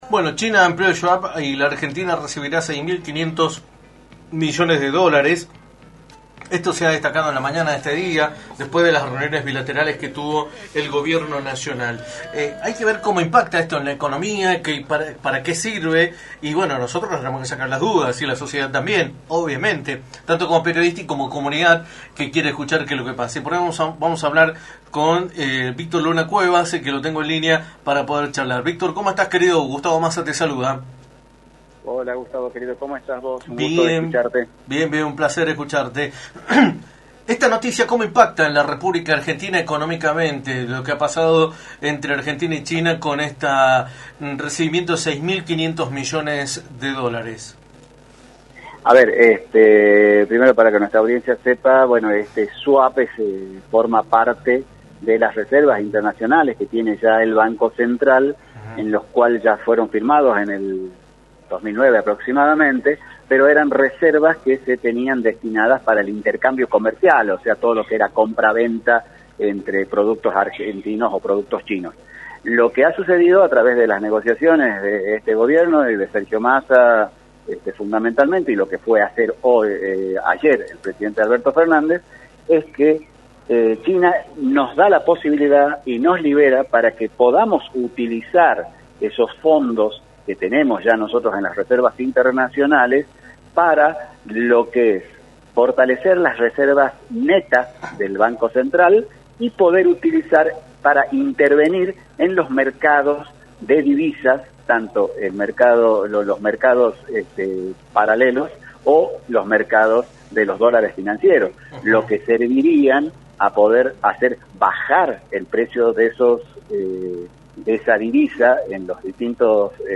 entrevista para «La Mañana del Plata», por la 93.9.